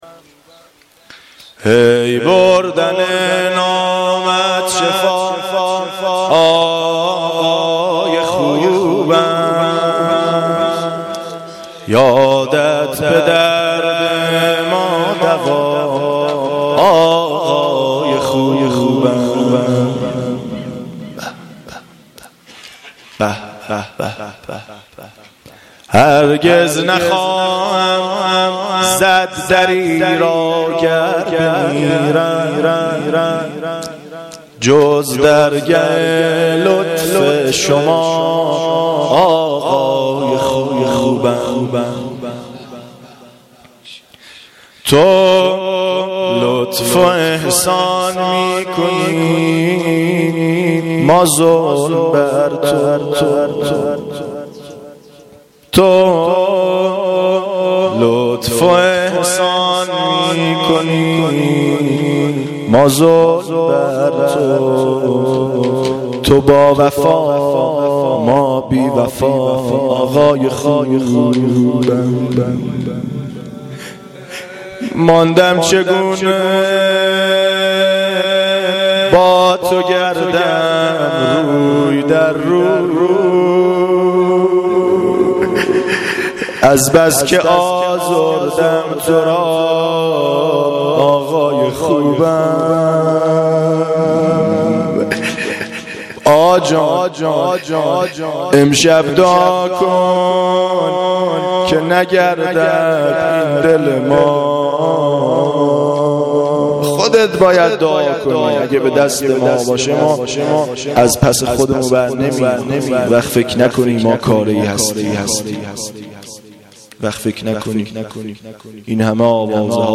مناجات
مناجات-پایانی.mp3